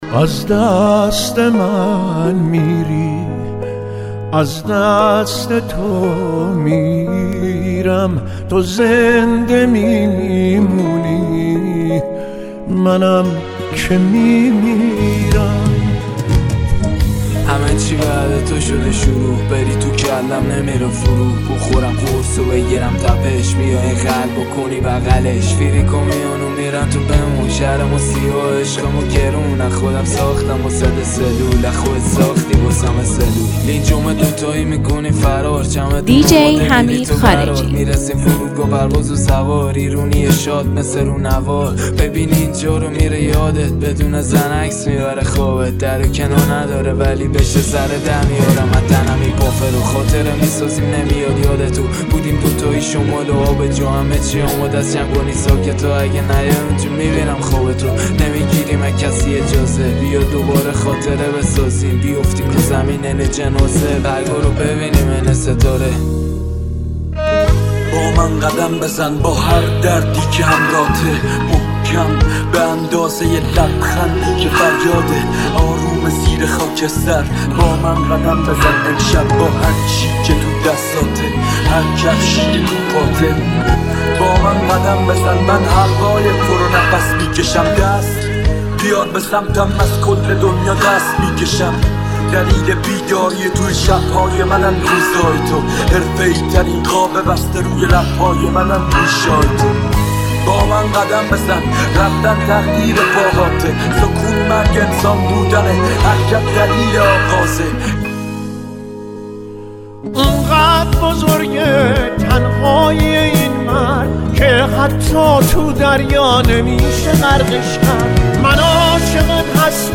آهنگ غمگین